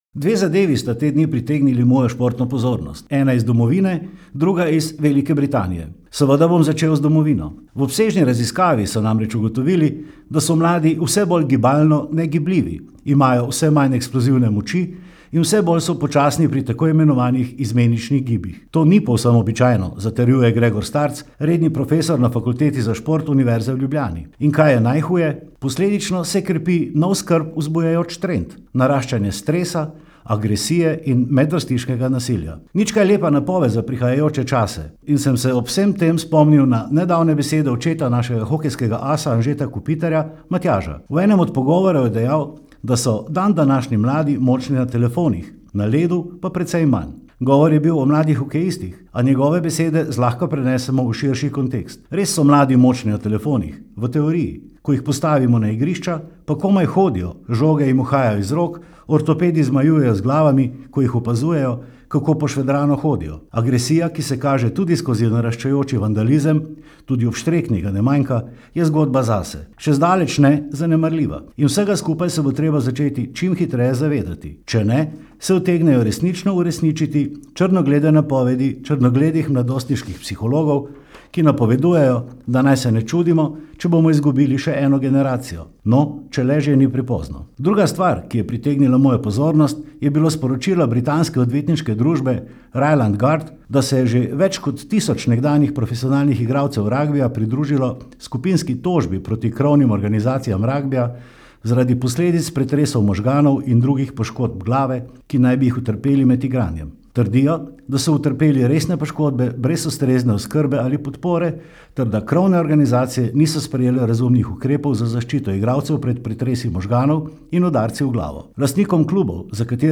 Komentar je mnenje avtorja in ne nujno tudi uredništva.